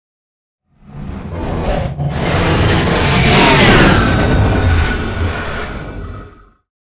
Scifi whoosh pass by long
Sound Effects
Atonal
futuristic
tension